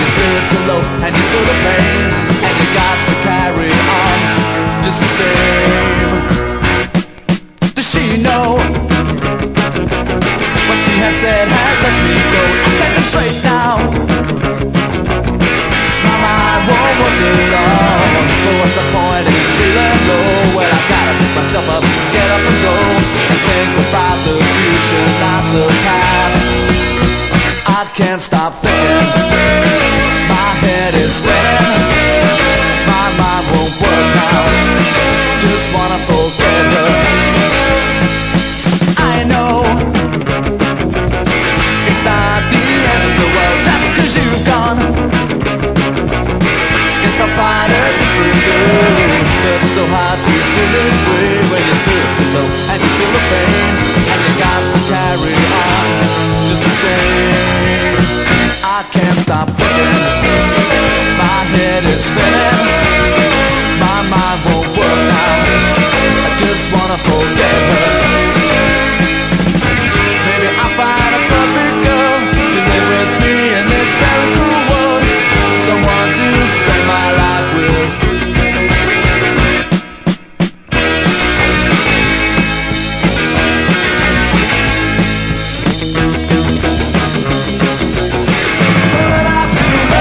ギターポップに通じるヌケ感も◎。